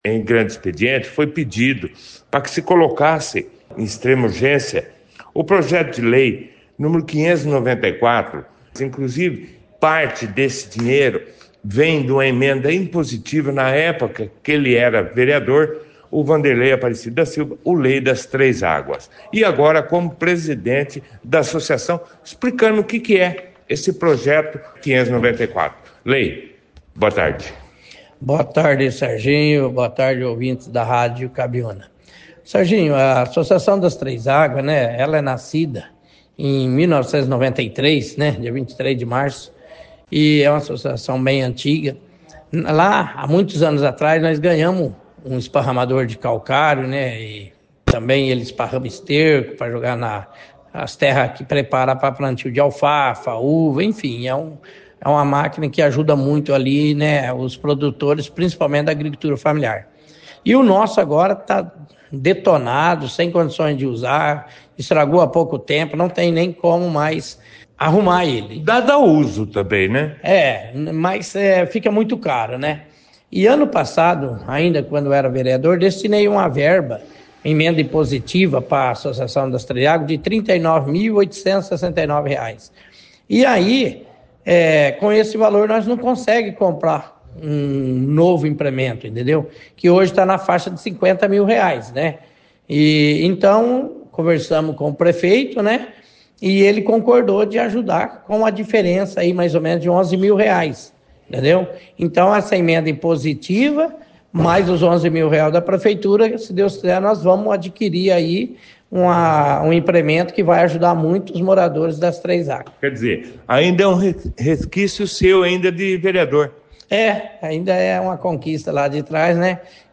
A Câmara Municipal de Bandeirantes realizou na noite de segunda-feira, dia 1º, a última sessão ordinária de 2025. e foi destaque na 2ª edição do jornal Operação Cidade desta terça-feira, 02.